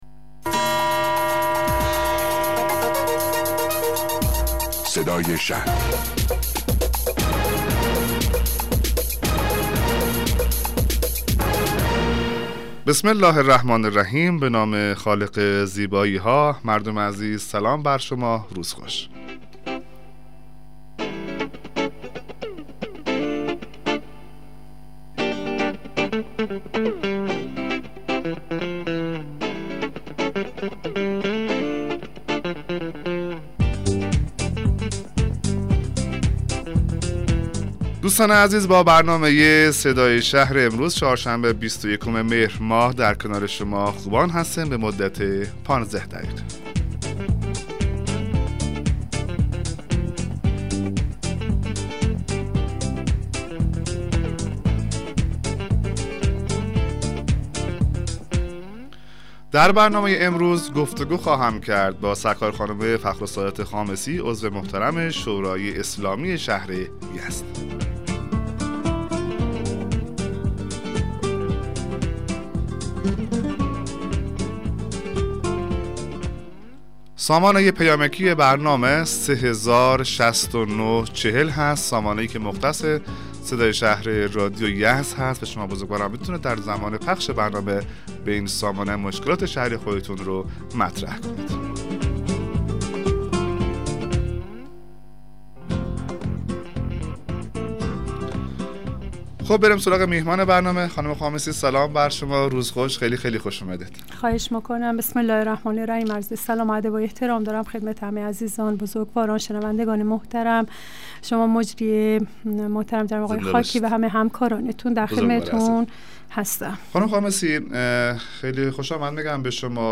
مصاحبه رادیویی برنامه صدای شهر با حضور فخرالسادات خامسی عضو شورای اسلامی شهر یزد